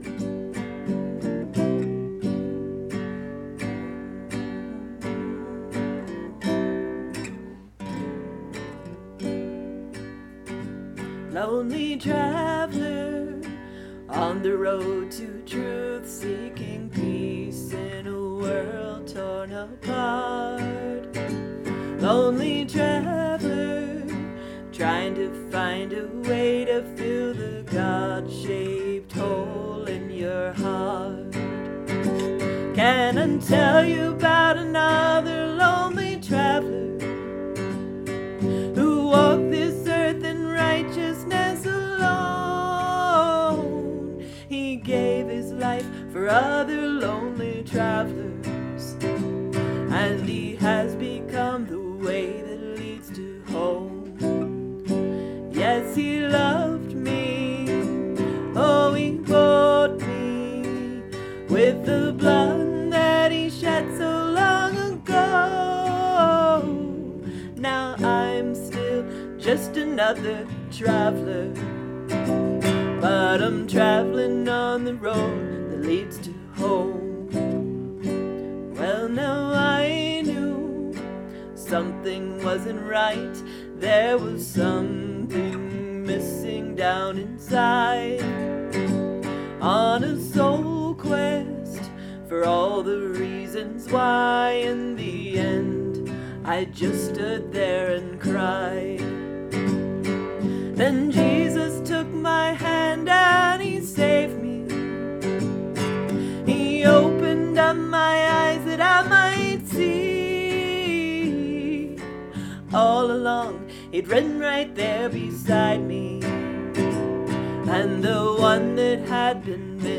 Capo 2nd fret